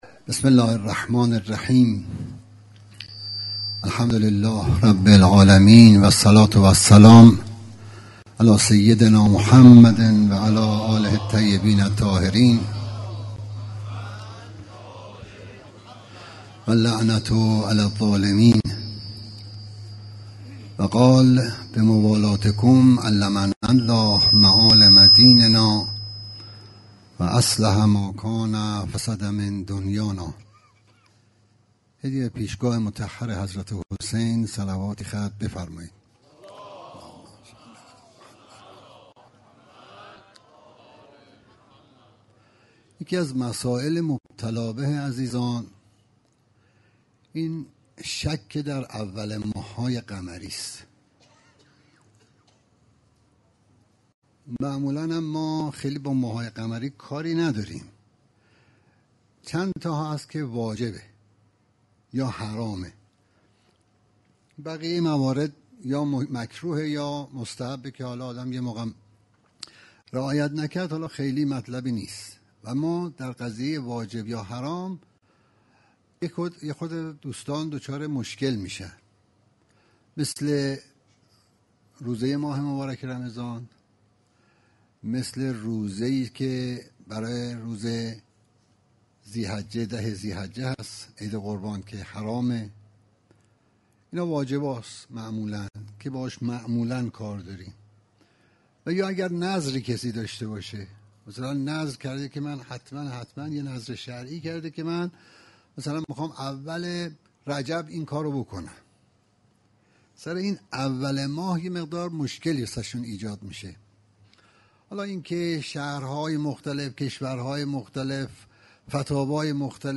سومین جلسه سوگواری دهه اول ماه محرم با محوریت شرح زیارت جامعه کبیره